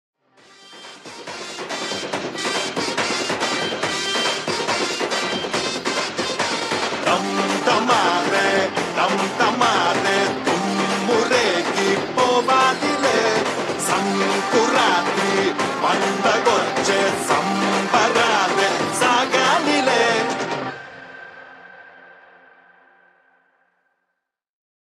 hindi ringtonebollywood ringtonedance ringtoneparty ringtone
best flute ringtone download